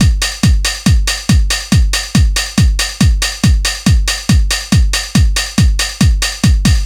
NRG 4 On The Floor 040.wav